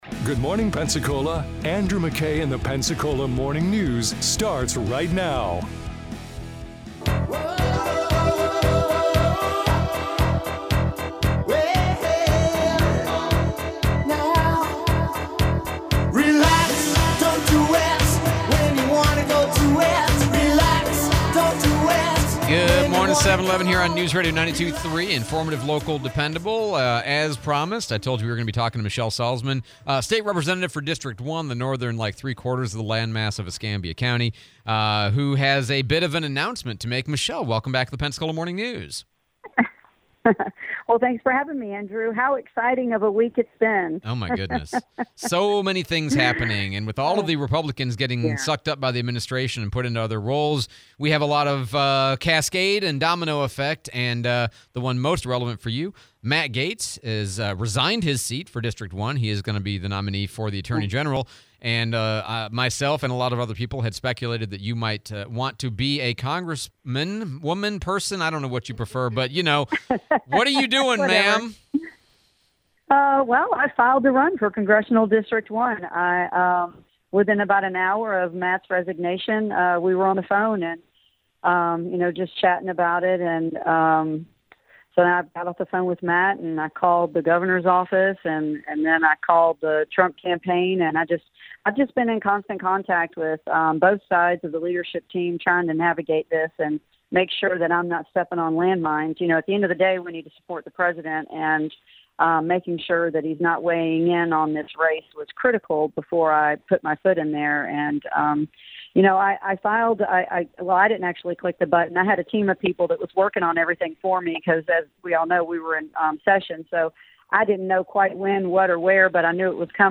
Interview with Representative Michelle Salzman / Interview with Senator Rick Scott